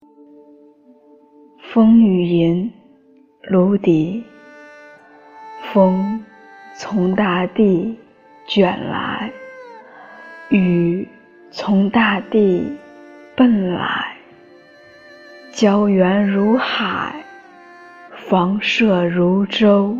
九年级语文下册 3《风雨吟》女声配乐朗读（音频素材）